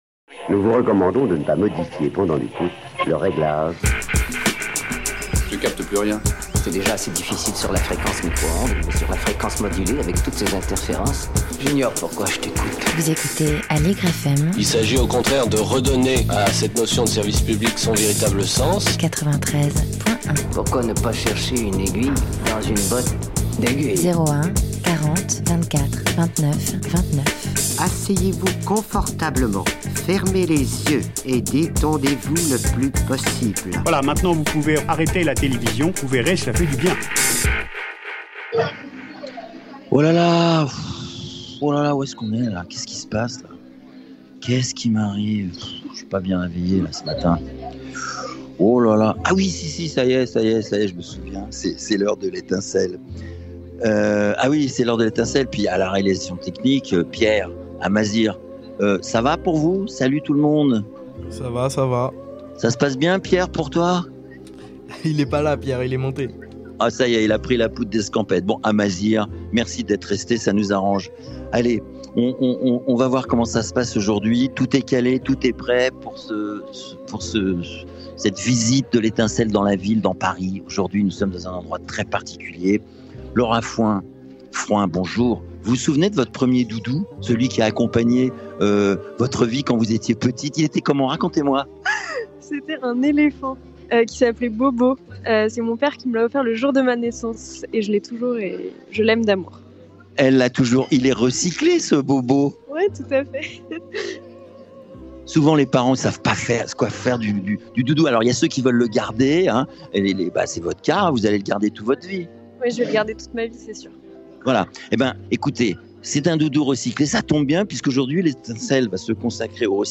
L'étincelle dans la ville vous propose une balade depuis la Maison du Zéro Déchet à Paris dans le 12e arrondissement.